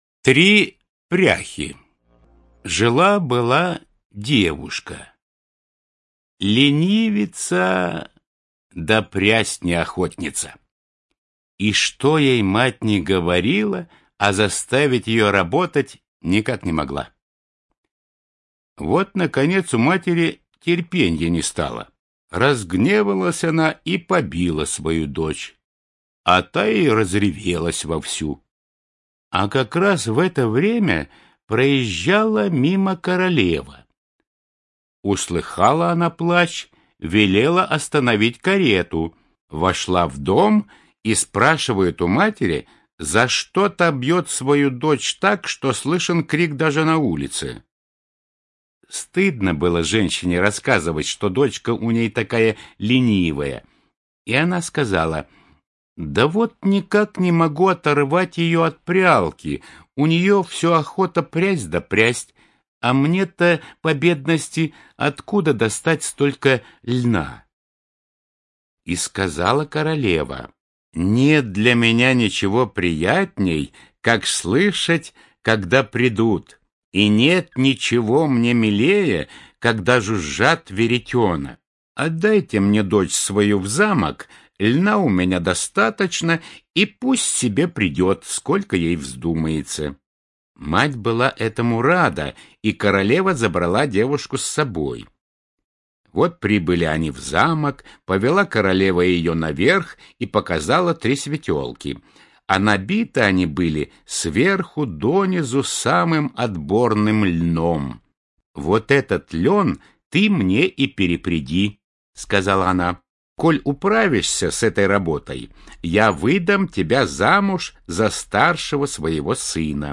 Три пряхи - аудиосказка братьев Гримм.